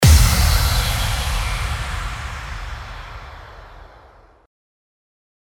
FX-782-IMPACT
FX-782-IMPACT.mp3